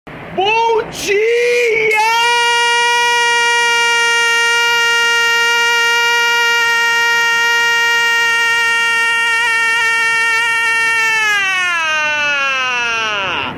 Homem grita bom dia demoradamente